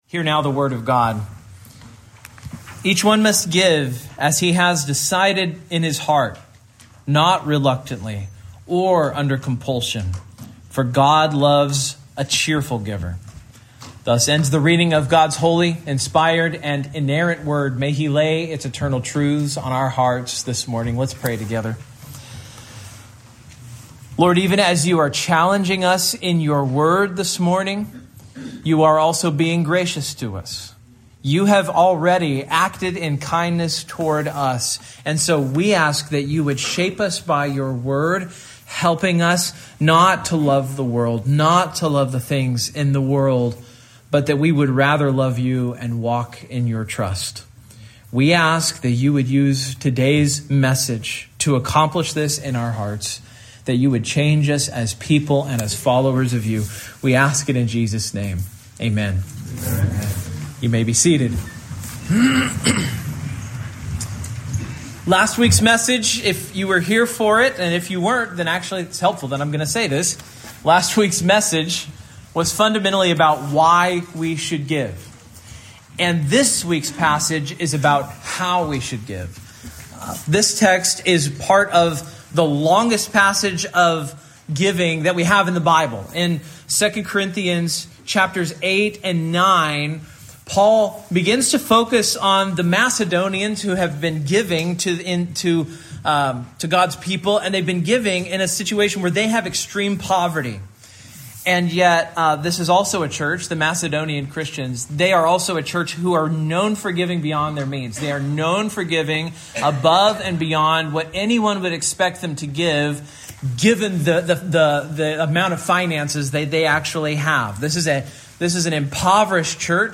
2 Corinthians 9:7 Service Type: Morning Main Point